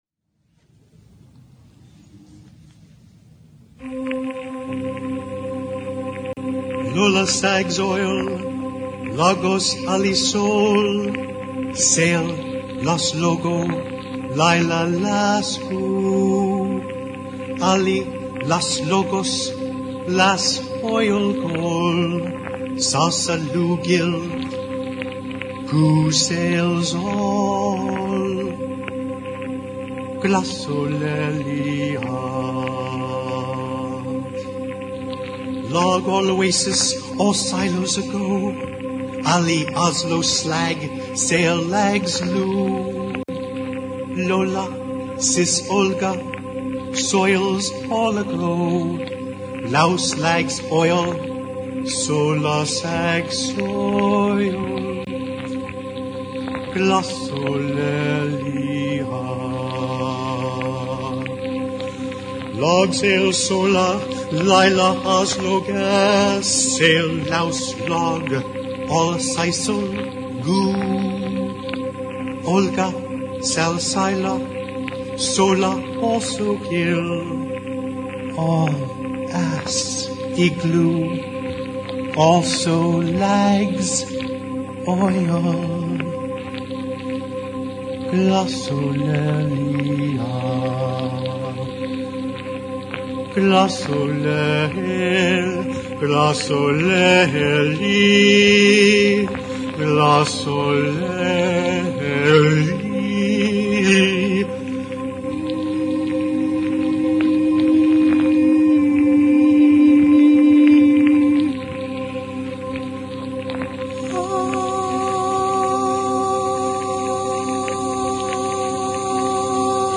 LIVE IN PERFORMANCE